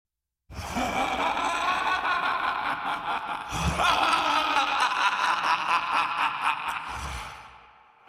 Laughter Track Sound Button: Unblocked Meme Soundboard
Laughter Track Sound Effects